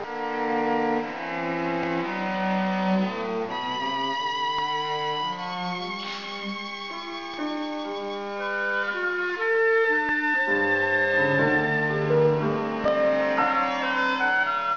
Ob, Cl, Vln, Vc, Harp   [Performance]